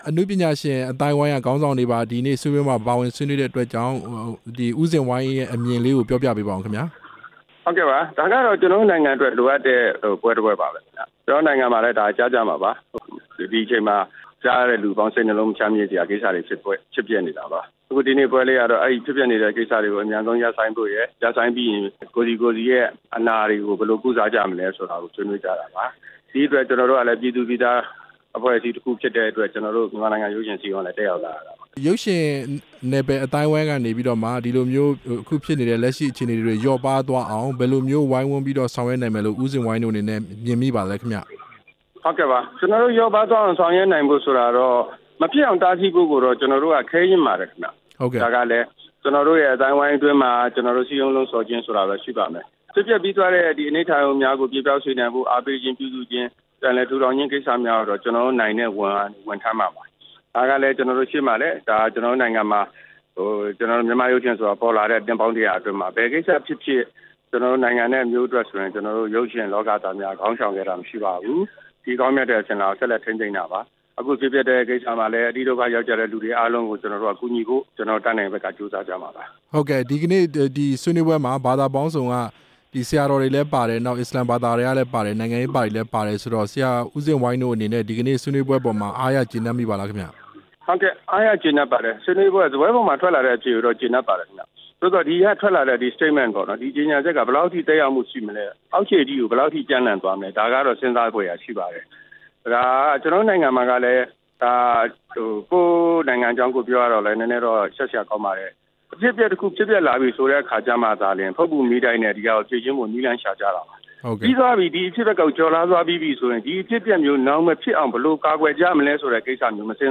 ရုပ်ရှင်အစည်းအရုံး ဥက္ကဌ ဦးဇင်ဝိုင်းနဲ့ မေးမြန်းချက်